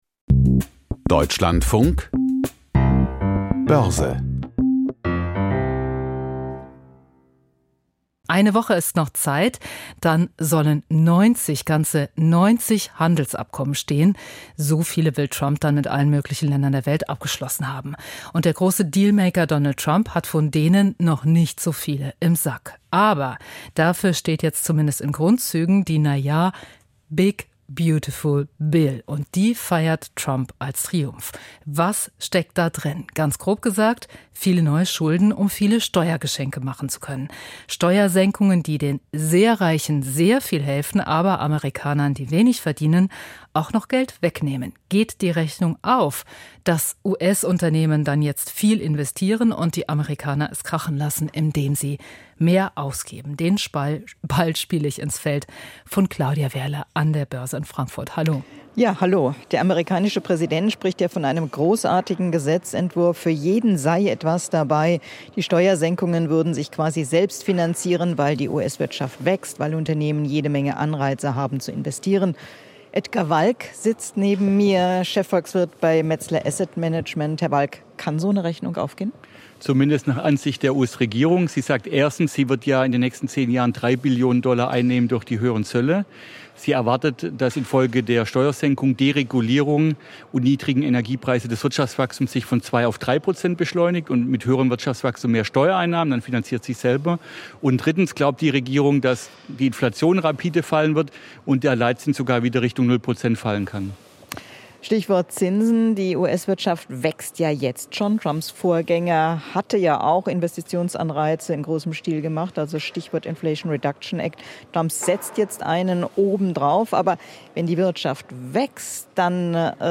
Börsenbericht aus Frankfurt